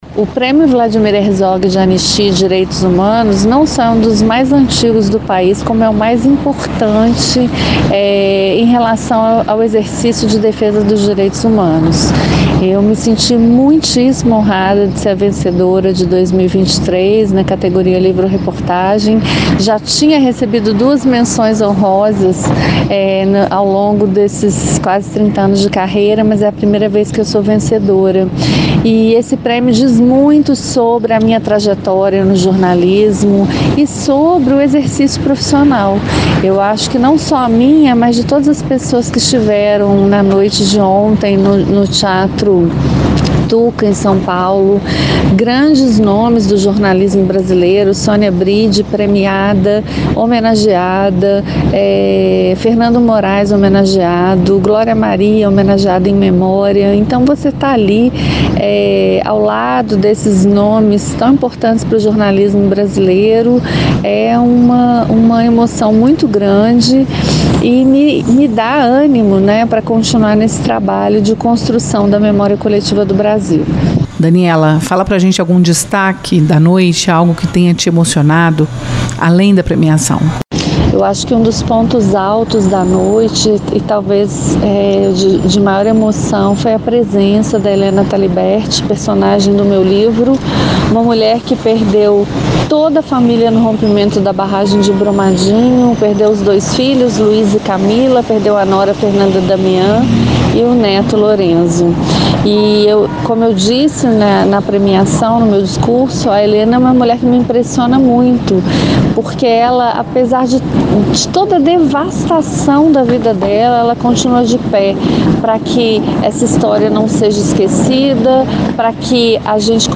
Em conversa com a Itatiaia, Daniela Arbex falou sobre a premiação, inédita na carreira.